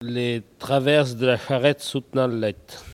Elle provient de Saint-Jean-de-Monts.
Catégorie Locution ( parler, expression, langue,... )